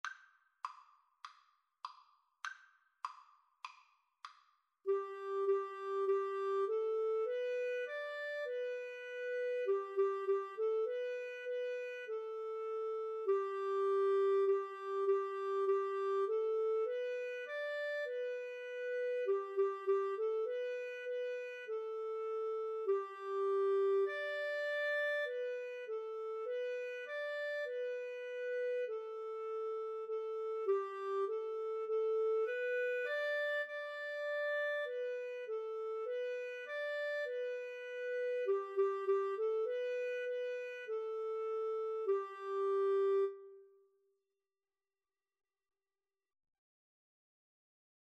ClarinetGuitar
4/4 (View more 4/4 Music)